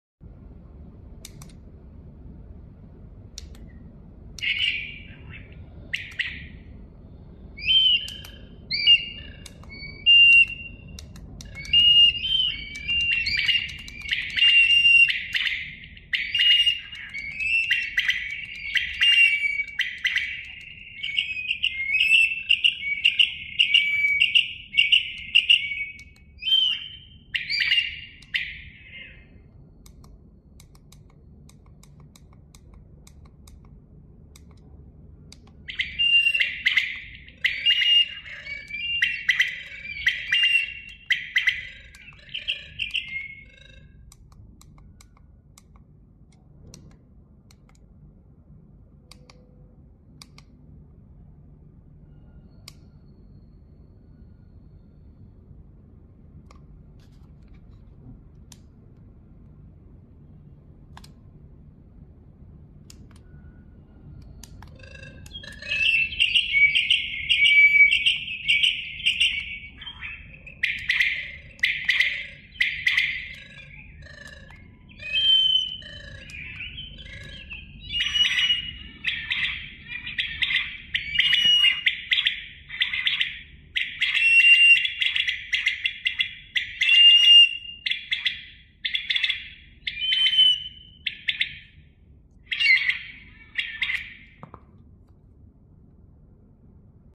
MIX 2 Bird Sounds Device Sound Effects Free Download
MIX 2 bird sounds device